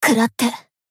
BA_V_Kayoko_Battle_Shout_3.ogg